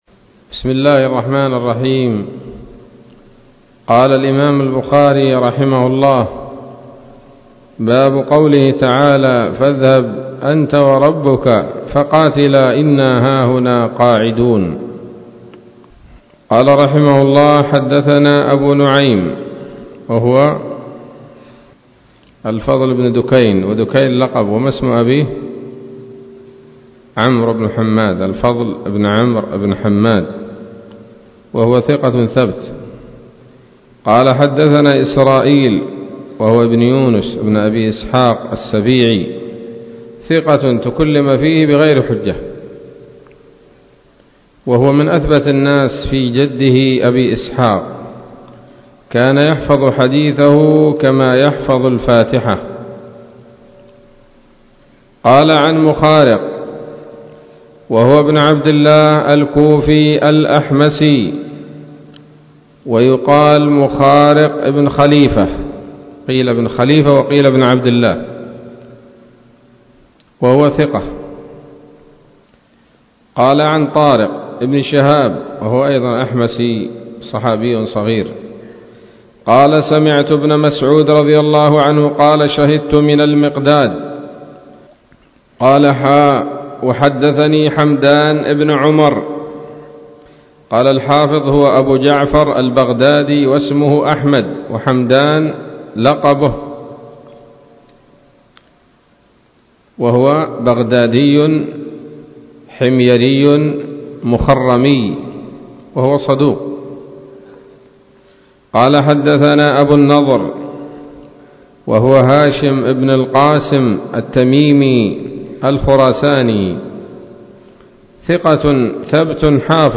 الدرس الثامن والثمانون من كتاب التفسير من صحيح الإمام البخاري